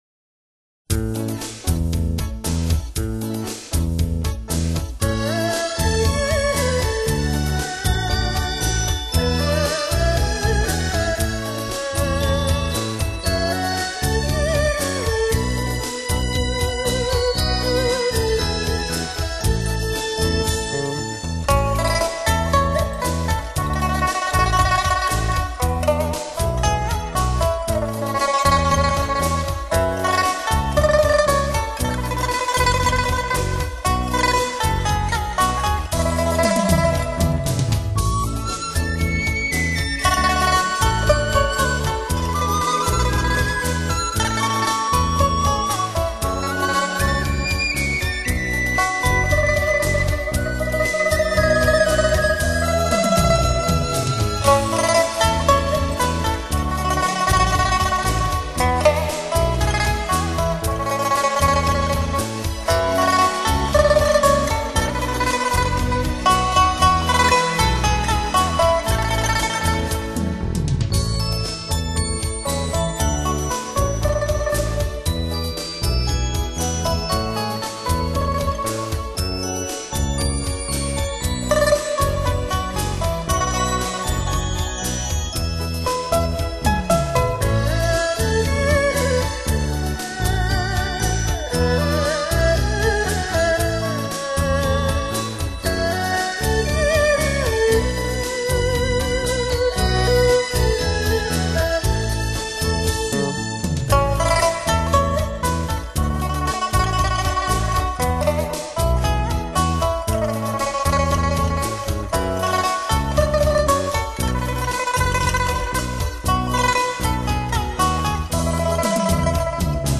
琵琶演奏